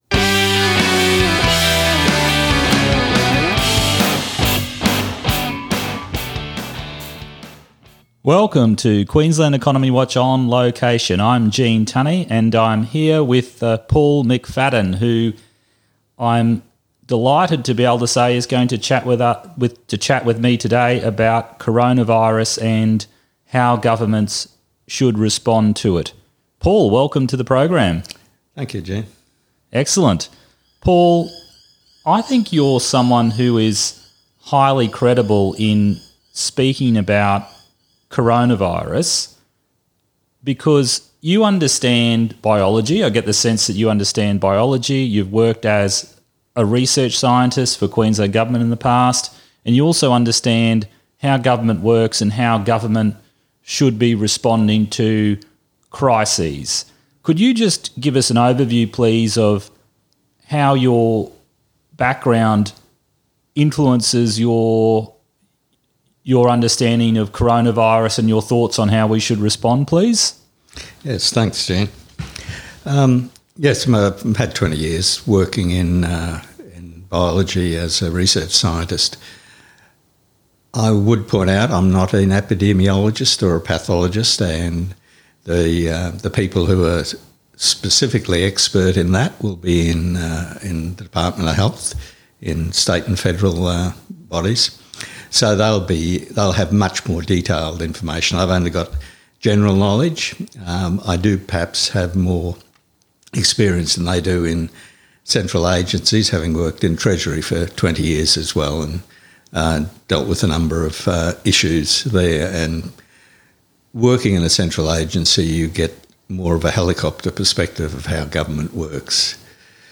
We spoke at his home in suburban Brisbane, which explains why you can hear some parrots chirping at times in the recording (below).